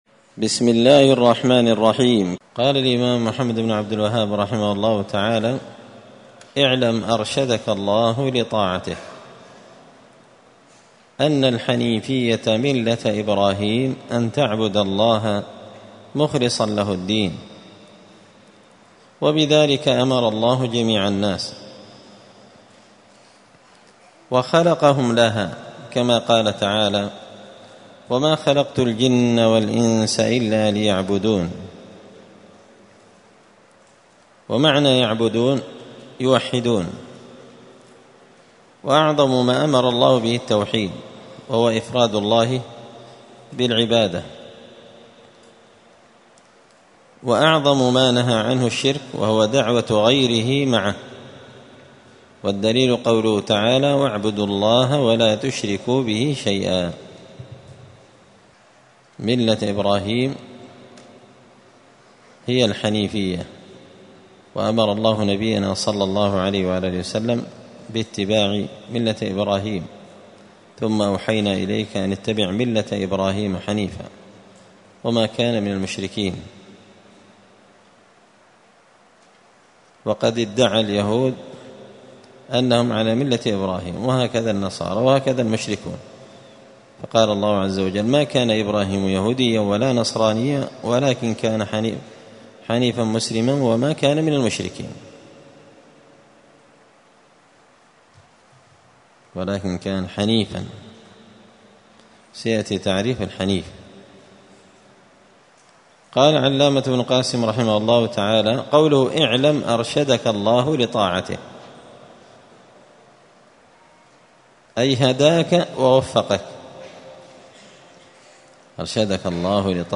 مسجد الفرقان قشن_المهرة_اليمن 📌الدروس الأسبوعية